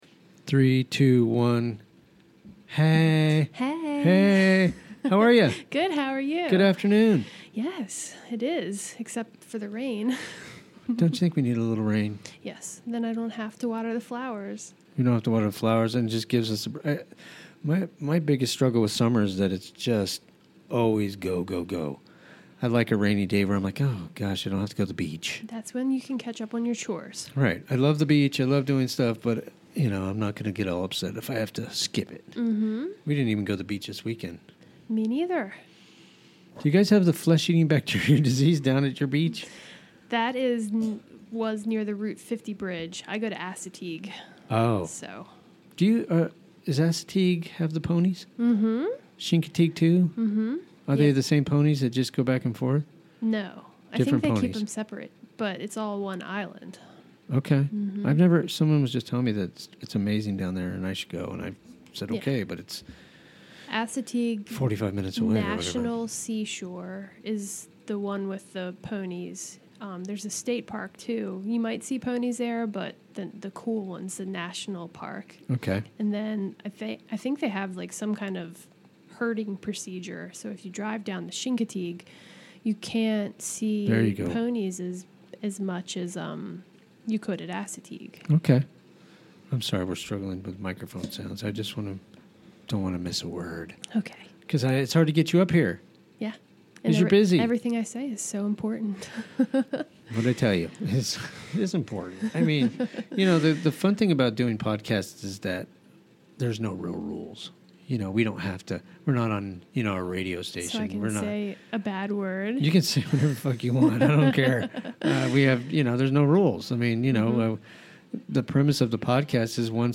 Chatting with local folks